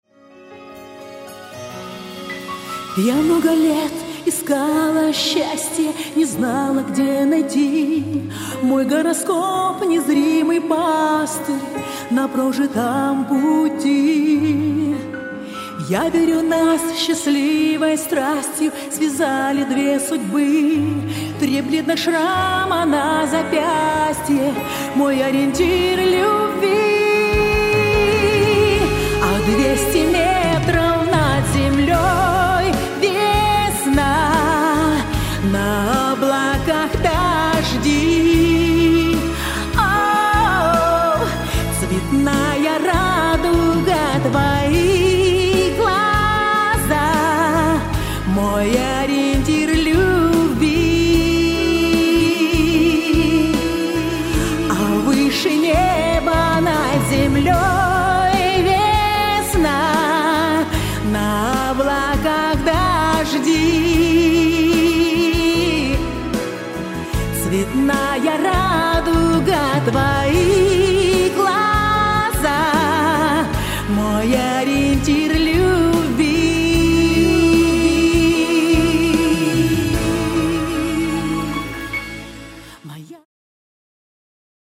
Ориентир любви ( запись и сведение)